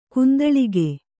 ♪ kundaḷige